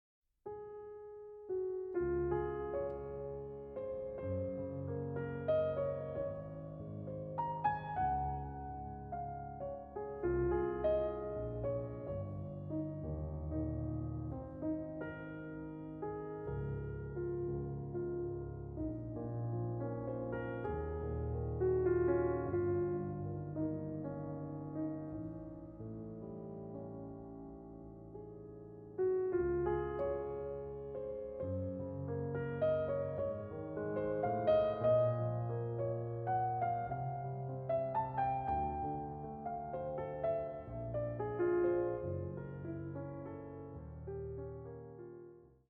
これらの「聴きやすい」曲にはクライマックスや終着点が無く、宙に浮くように美しい叙情性だけがいつまでも残っていきます。